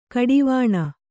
♪ kaḍivāṇa